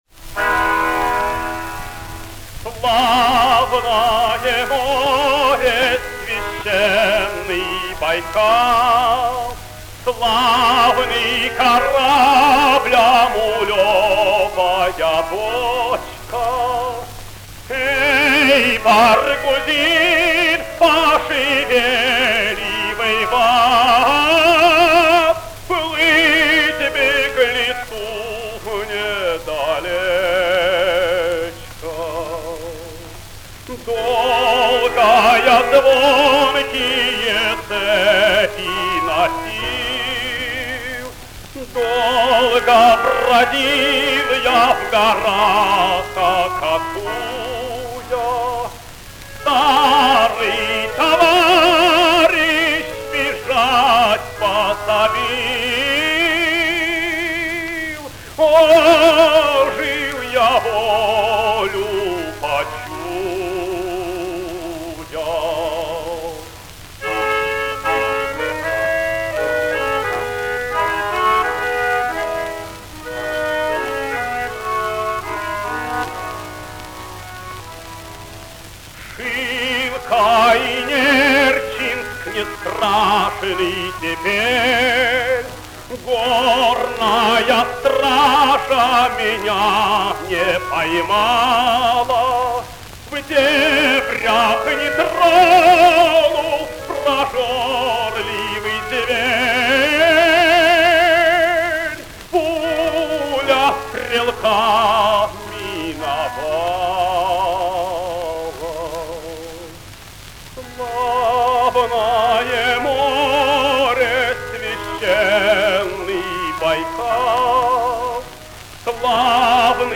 Описание: Необычное теноровое исполнение знаменитой песни.
в сопр. баяна